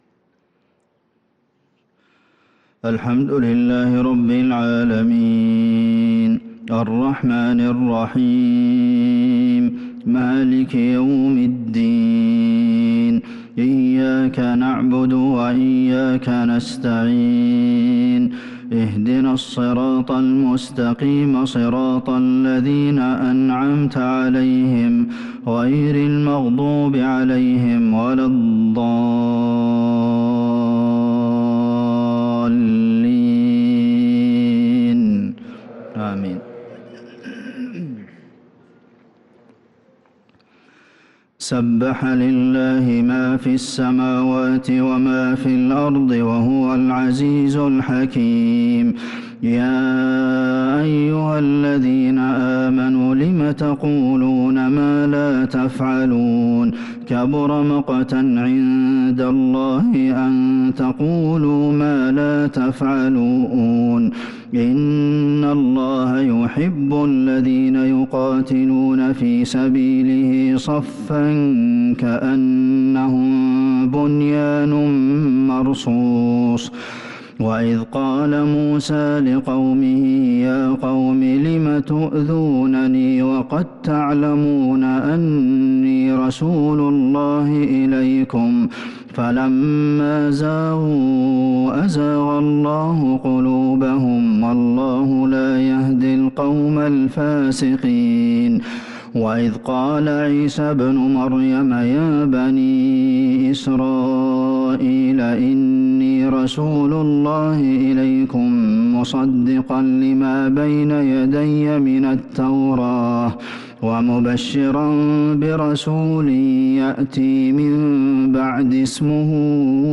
صلاة العشاء للقارئ عبدالمحسن القاسم 22 شعبان 1443 هـ
تِلَاوَات الْحَرَمَيْن .